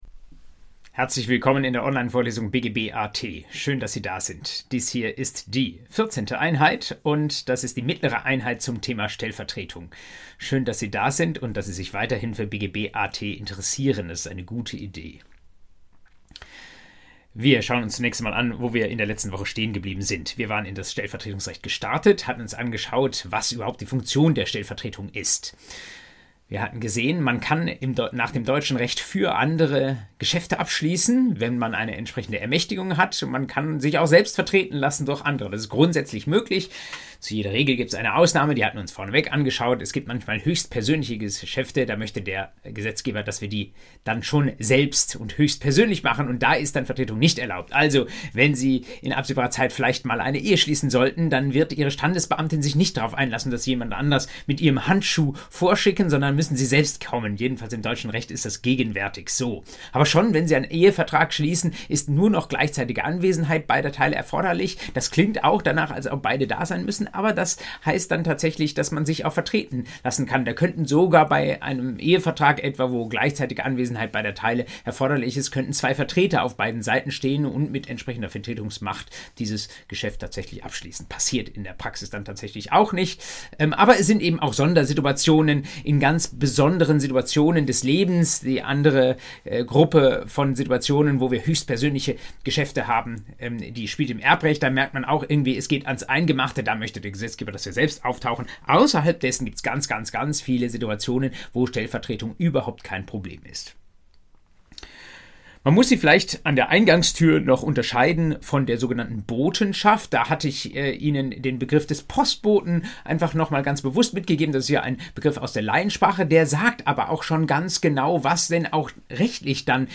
BGB AT Folge 14: Stellvertretung II ~ Vorlesung BGB AT Podcast